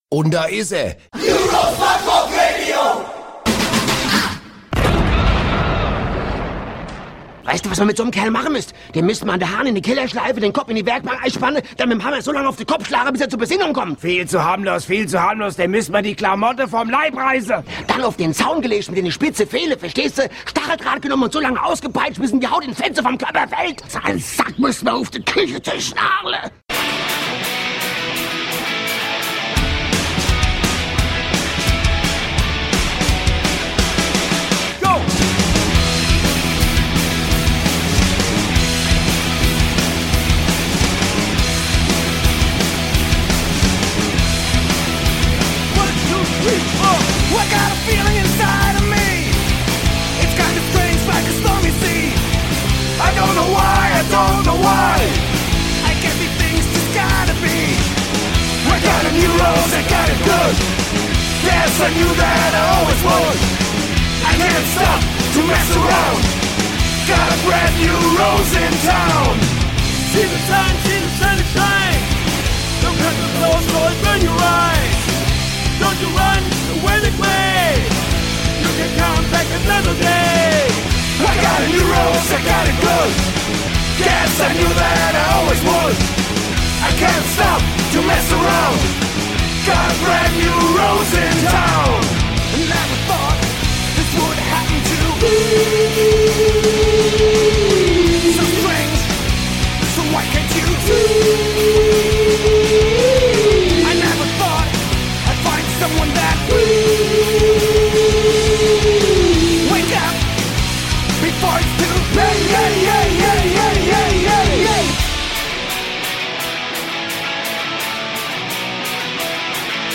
Räudiges Ratten-Radio aus Berlin!